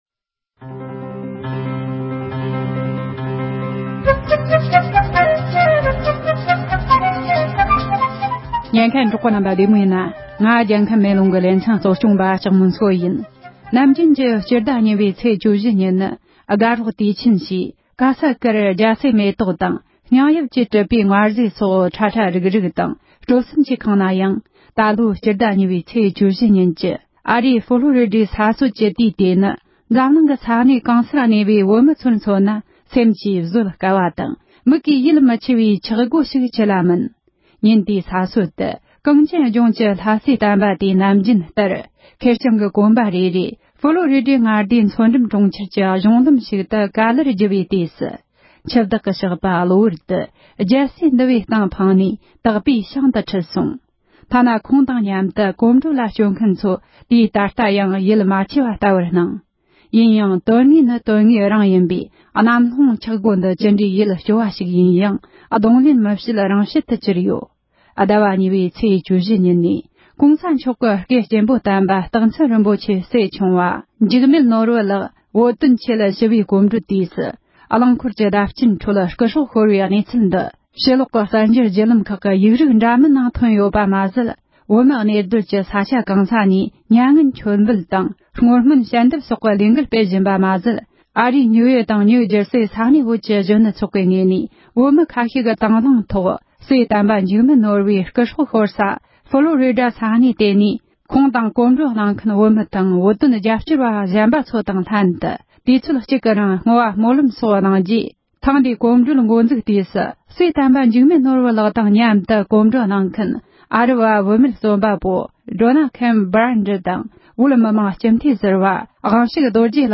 འབྲེལ་ཡོད་མི་སྣར་བཅར་འདྲི་ཞུས་པ